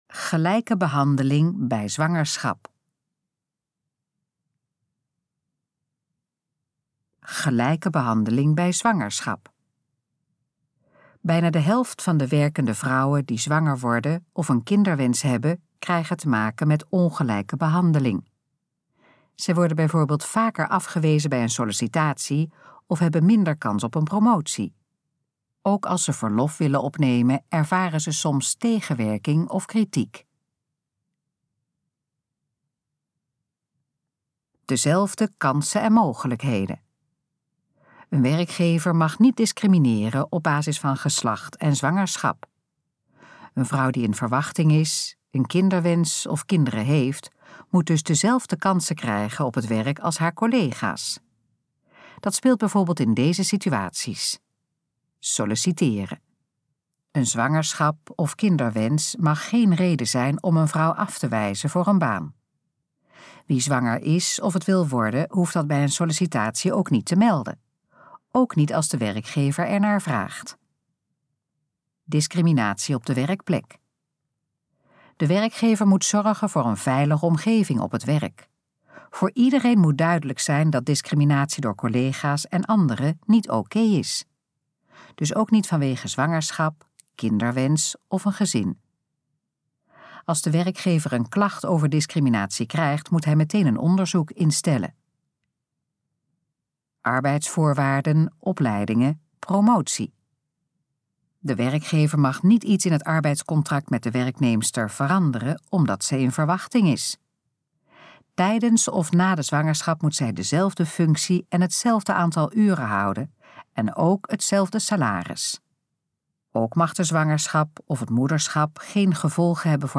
Gesproken versie van: Gelijke behandeling bij zwangerschap
Dit geluidsfragment is de gesproken versie van de pagina: Gelijke behandeling bij zwangerschap.